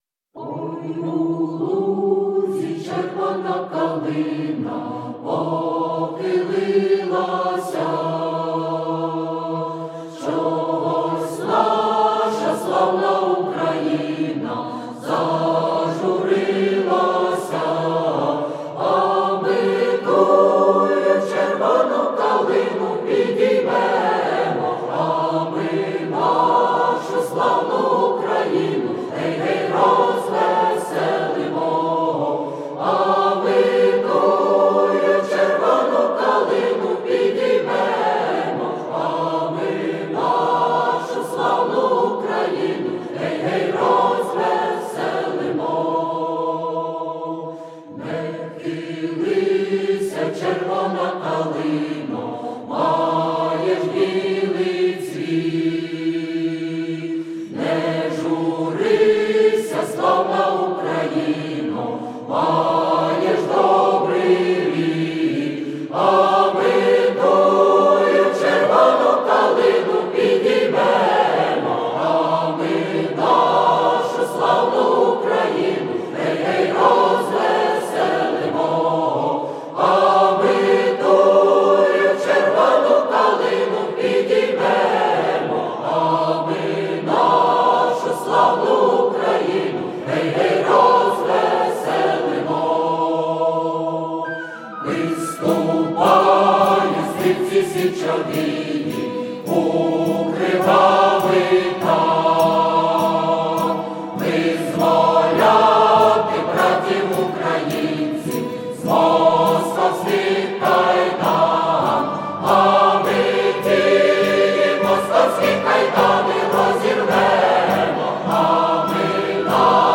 Етнографічний хор "Гомін"